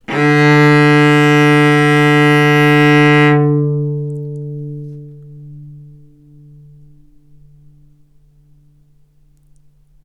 vc-D3-ff.AIF